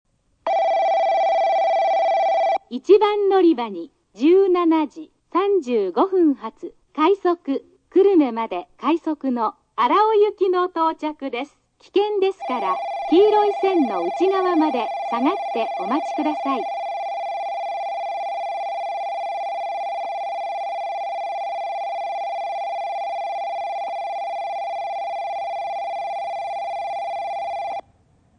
スピーカー：ソノコラム
接近放送（快速・荒尾）　(138KB/28秒)
接近放送時に「危険ですから」のフレーズを喋らないのが特徴であり、形態は異なるものの、博多駅でもこのフレーズは存在しない。